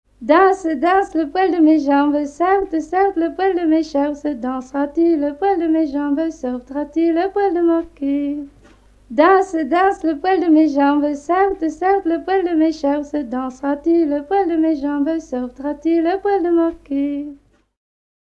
Couplets à danser
branle : courante, maraîchine
Pièce musicale éditée